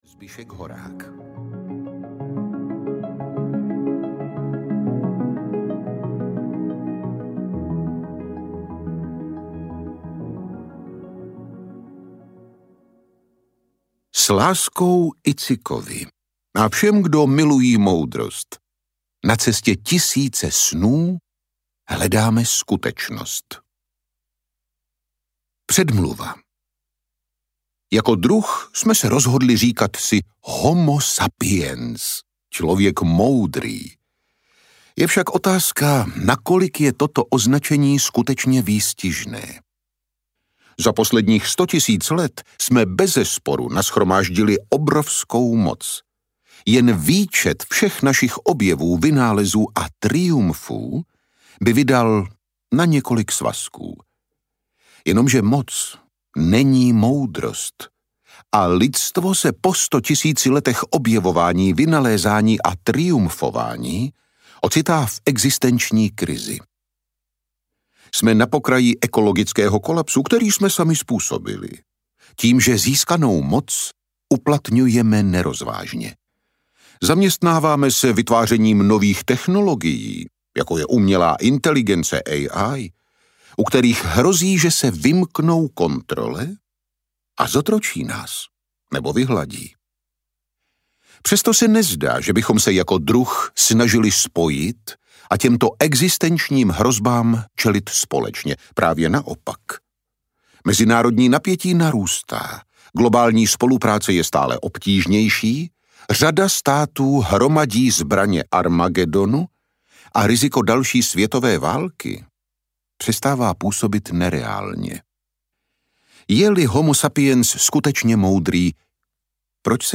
Nexus audiokniha
Ukázka z knihy
nexus-audiokniha